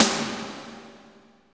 53.04 SNR.wav